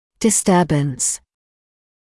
[dɪ’stɜːbəns][ди’стёːбэнс]нарушение; расстройство; патологическое отклонение